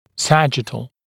[‘sæʤɪtl][‘сэджитл]сагиттальный